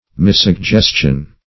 What does missuggestion mean?
Search Result for " missuggestion" : The Collaborative International Dictionary of English v.0.48: Missuggestion \Mis`sug*ges"tion\ (? or ?), n. Wrong or evil suggestion.